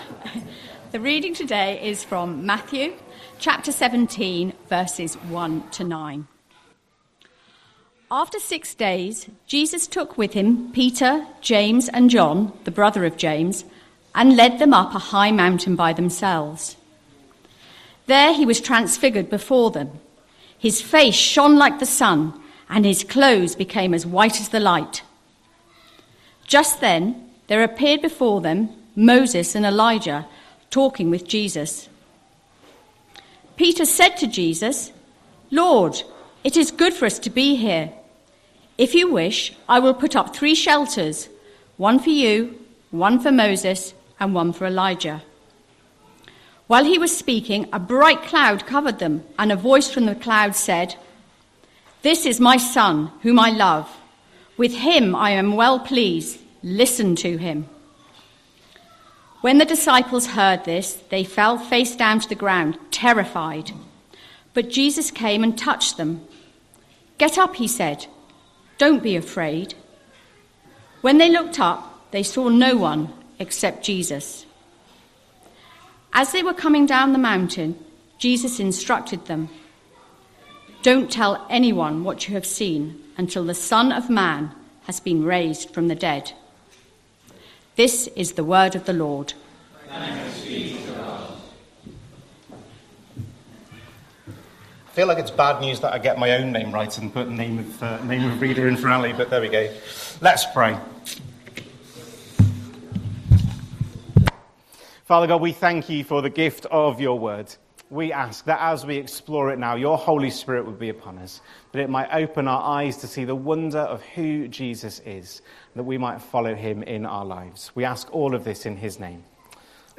15th February 2026 Sunday Reading and Talk - St Luke's